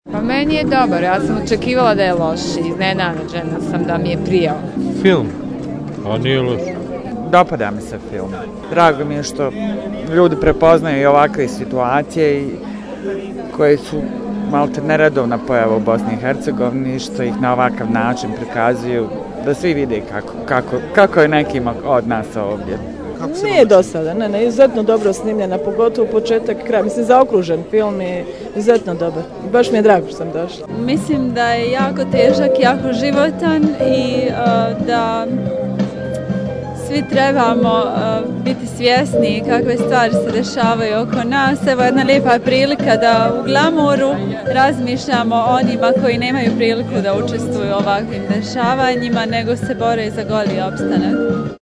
Pored u Narodnom pozorištu, priređena je projekcija njegovog ostvarenja i u Otvorenom kinu Metalac gdje je više od 3.000 ljudi velikim aplauzom pozdravilo kompletnu ekipu filma „Epizoda u životu berača željeza“.
Publika o filmu „Epizoda u životu berača željeza“